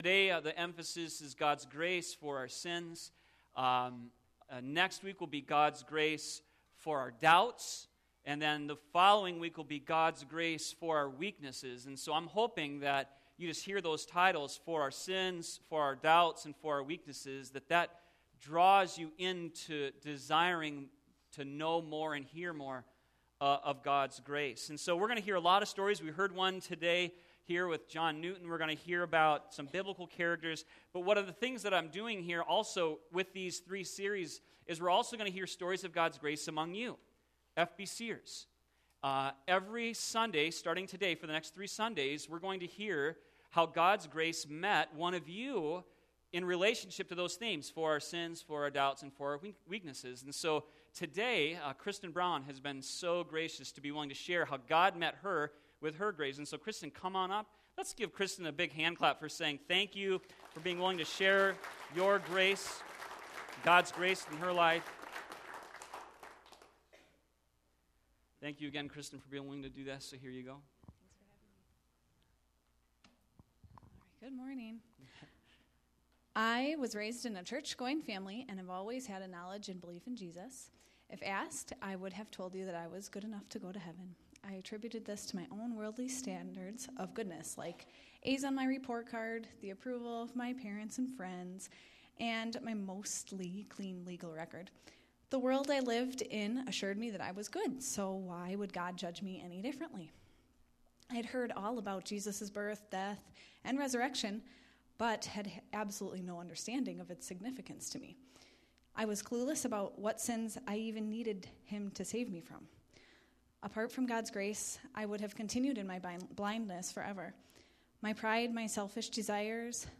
sermon1514.mp3